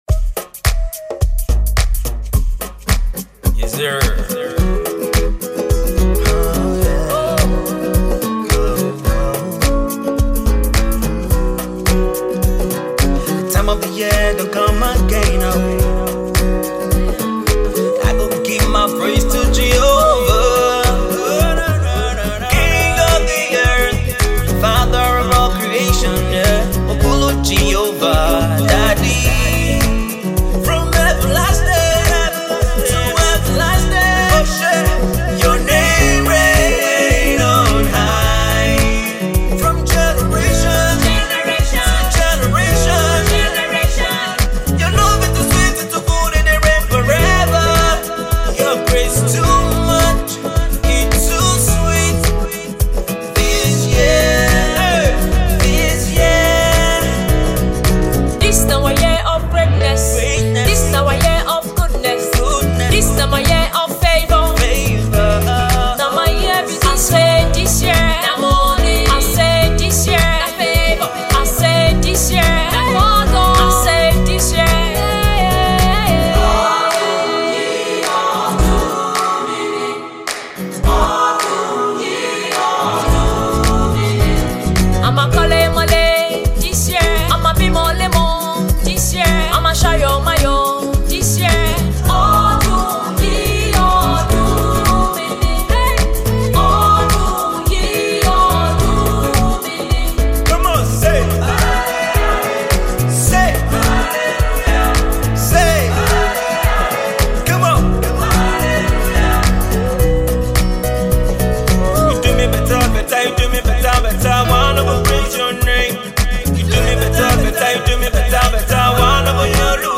Nice but , long ass verses and too much autotune